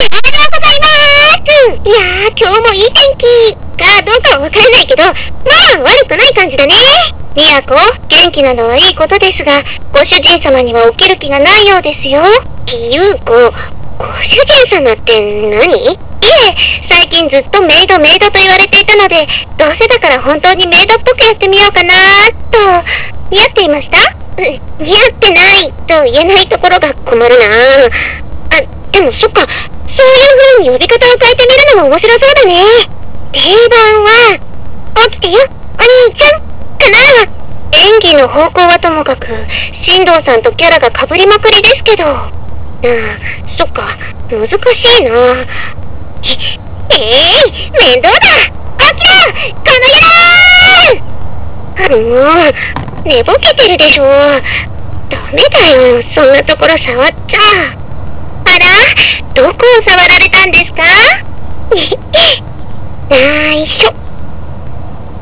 Clock.WAV